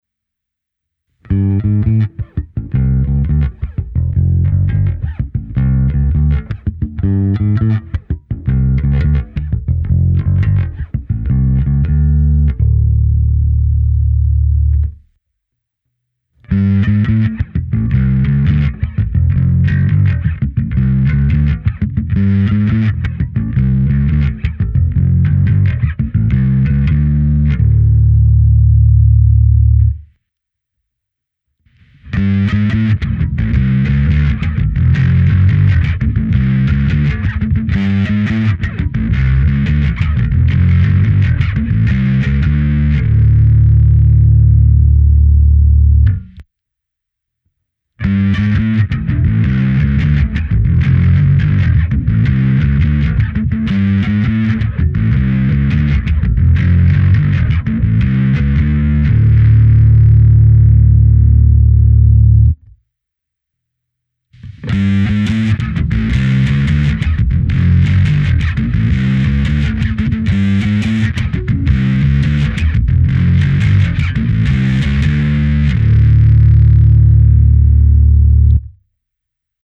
Zvuk je z říše overdrive, prostě taková klasika, při extrémním nastavení dokáže zasáhnout až do říše distortionu.
Nahrál jsem ukázky s baskytarou Fender American Professional II Precision Bass V s roundwound niklovými strunami Sadowsky Blue Label v dobrém stavu. V nahrávkách jsem použil vždy kompresor, lehkou ekvalizaci a simulaci aparátu snímaného kombinací linky a mikrofonu. V první části je jen zvuk baskytary bez zařazení pedálu, pak navazují čtyři ukázky postupně rostoucího zkreslení. První zkreslovací ukázka představuje jen lehké nakreslení (crunch), druhá pak výraznější overdrive, ale stále s notnou částí původního signálu. Třetí a čtvrtá ukázka představují maximální dosažitelné zkreslení, tedy BLEND i DRIVE naplno, první z těchto dvou má úplně stažené TONE druhá (poslední ukázka) pak naopak naplno.